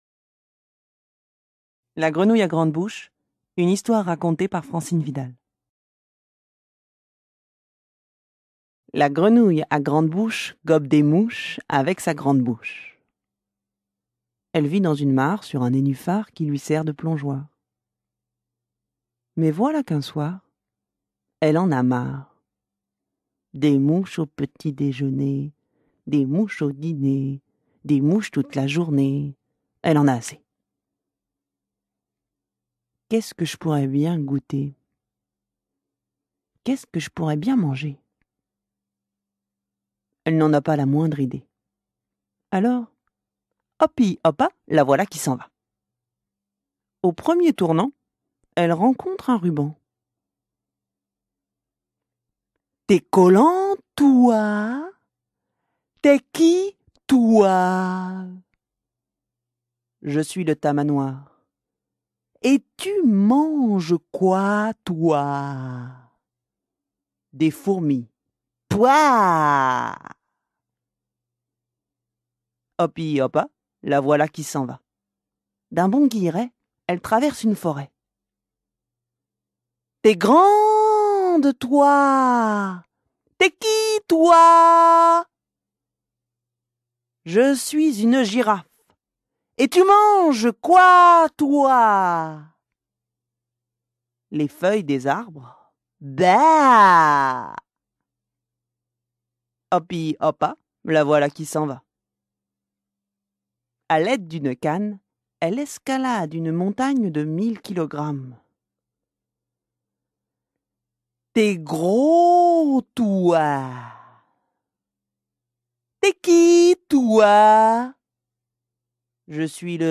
Je vais vous raconter l’histoire de la grenouille à grande bouche que vous allez me raconter ensuite..
La-Grenouille-à-grande-bouche-Histoire-racontée-192-kbps.mp3